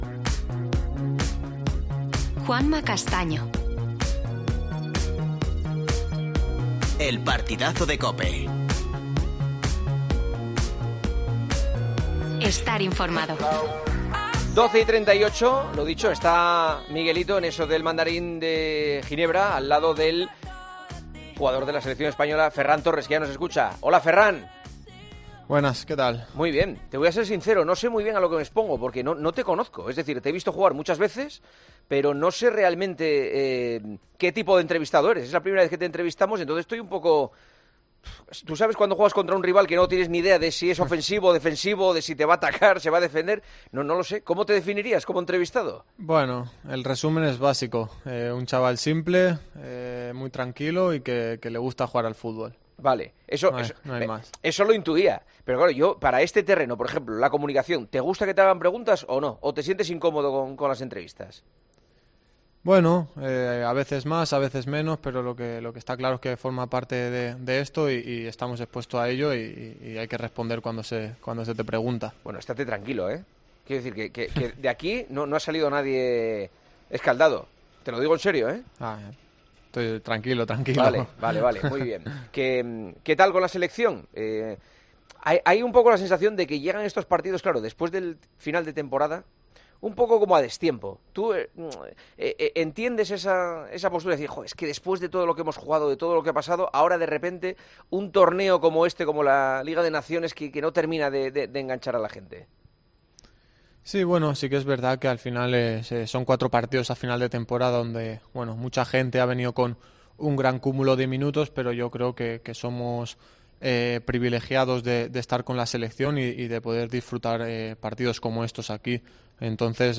AUDIO: Juanma Castaño entrevista al delantero del Barcelona y de la selección española para hablar la actualidad del club azulgrana y sus primeros meses en el...